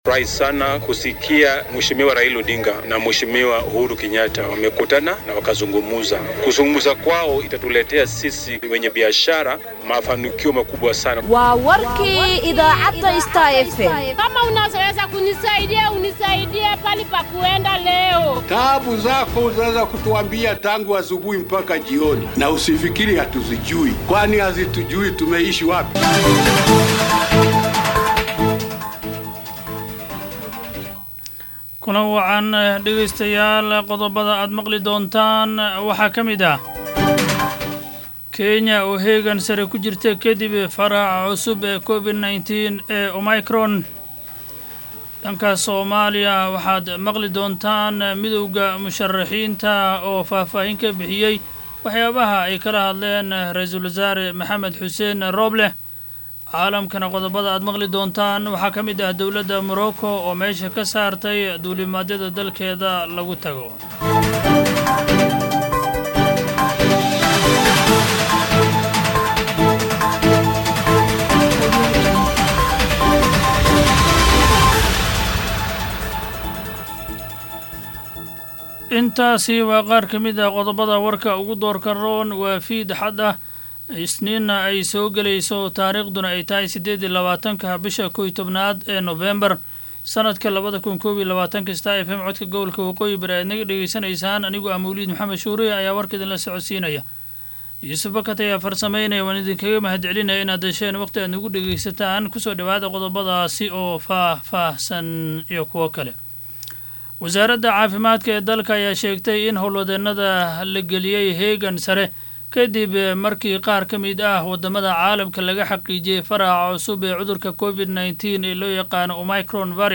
DHAGEYSO:WARKA HABEENIMO EE IDAACADDA STAR FM